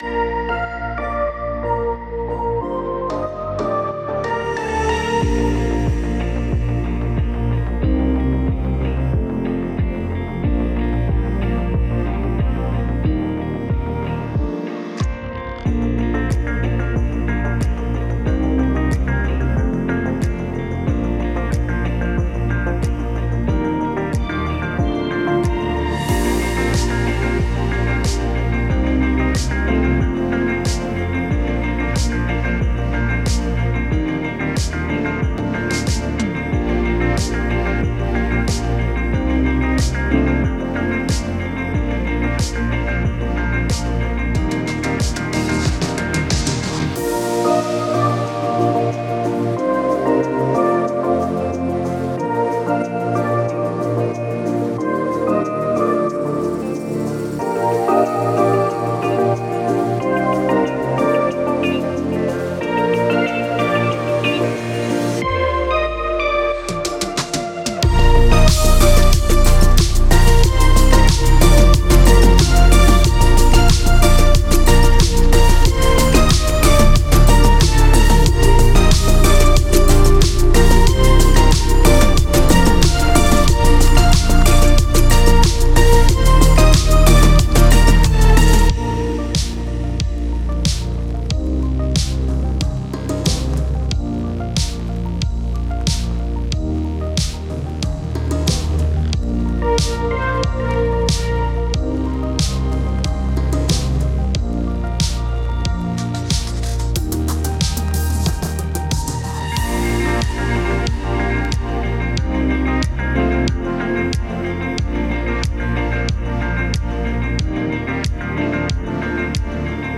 Genre: dancepop, electropop.